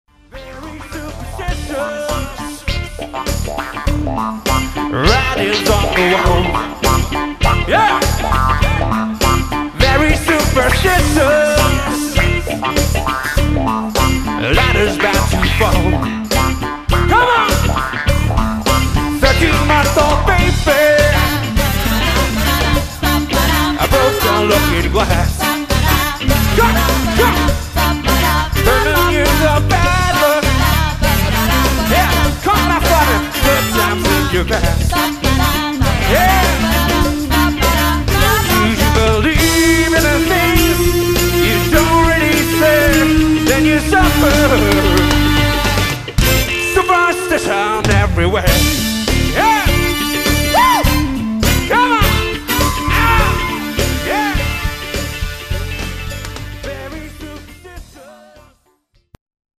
erstklassigen Soul, Pop, R´n´B und HipHop
Drums
Percussion/Samples
Vocals/Rap
Keyboards
Bass
Gitarre